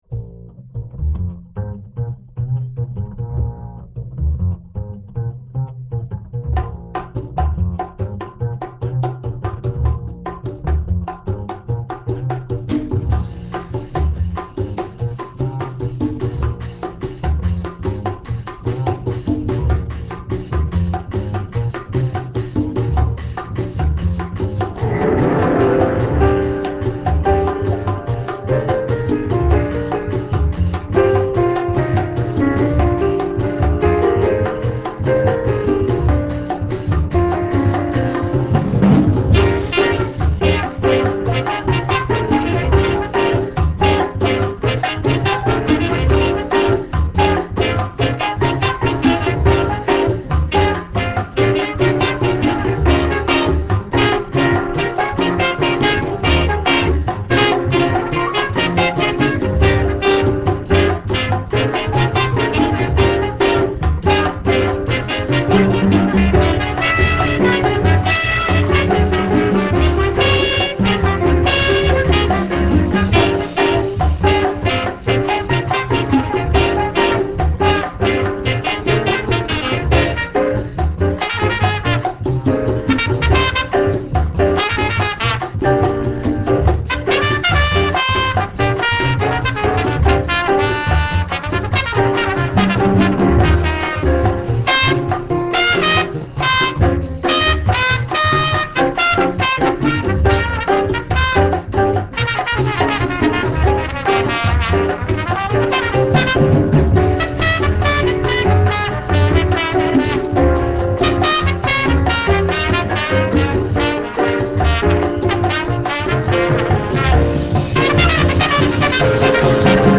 alto saxophone
trumpet
piano
bass
congas
drums